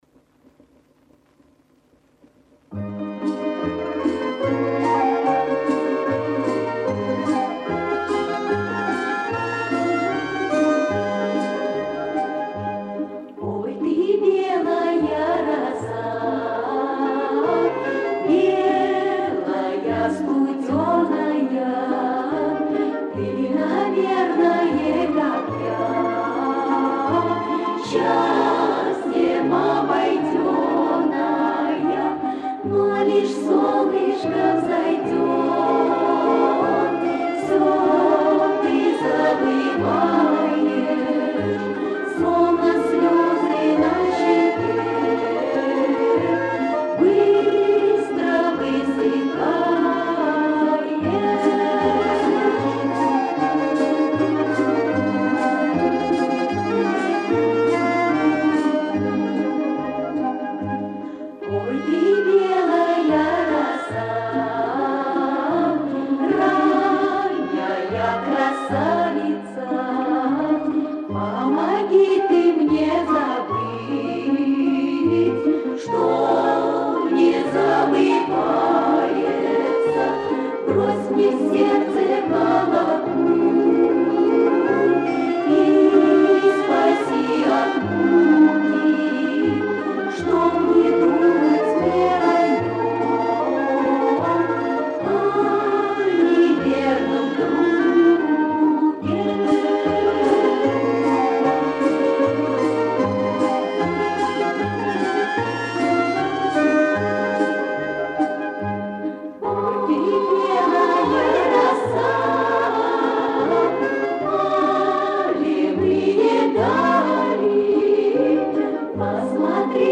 Спасибо, правда звук не очень